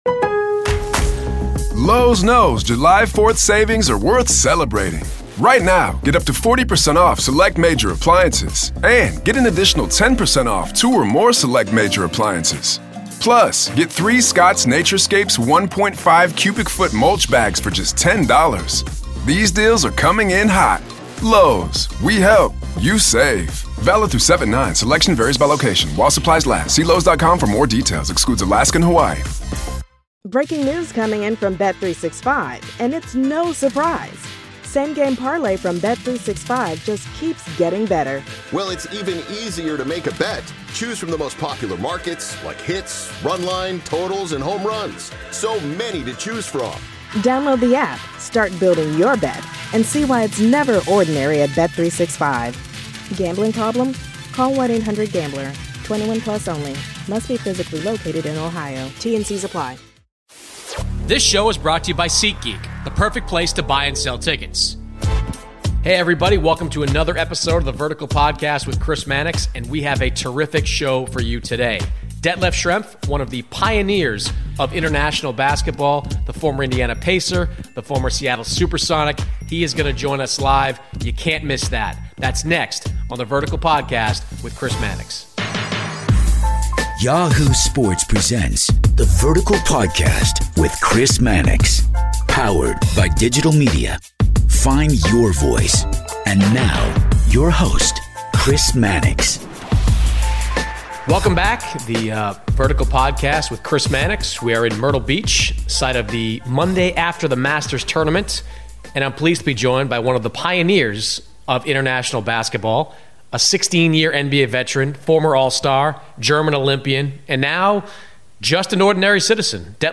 Former NBA player Detlef Schrempf joins the Vertical this week to discuss being one of the pioneers for the NBA in bring players from Europe to play in the league, and also what it was like playing against the 1995-1996 Chicago Bulls and how this years G